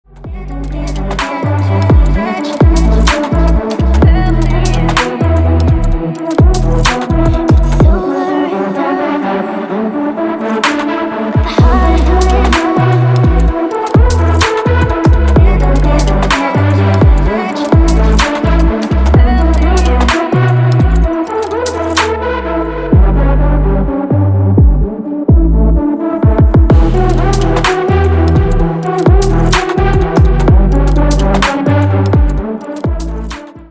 • Качество: 320, Stereo
женский голос
Electronic
спокойные
chillout
Стиль: chilled beats